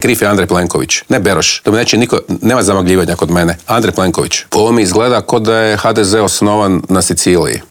ZAGREB - Premijer, ministri, šefovi oporbenih stranaka, gradonačelnici - svi oni bili su gosti Intervjua tjedna Media servisa.
Upravljanje SDP-om preuzeo je Siniša Hajdaš Dončić i pred našim kamerama udario po HDZ-u zbog afere Beroš.